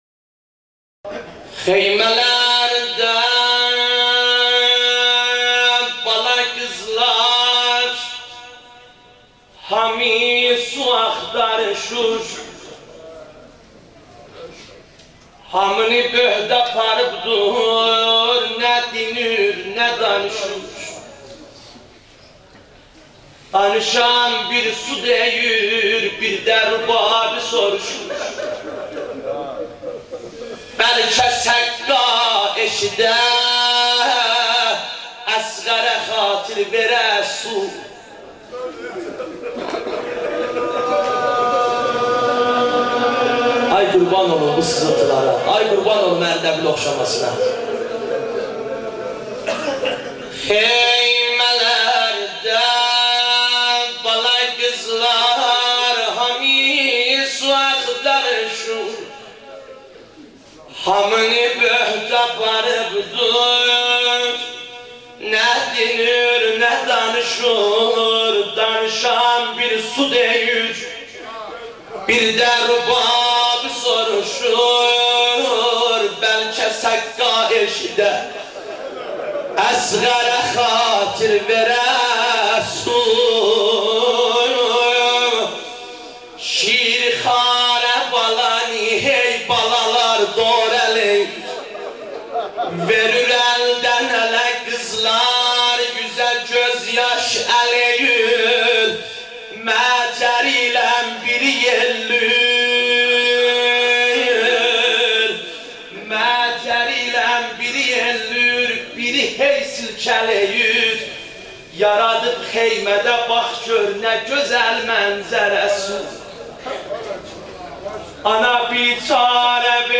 شب هفتم محرم مداحی آذری نوحه ترکی